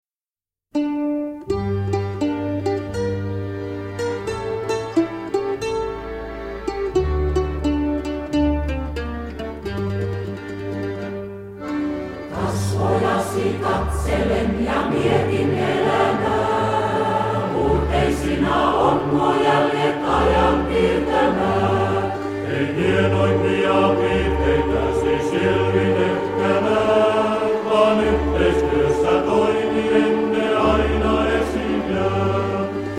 kontrabasso
mandoliini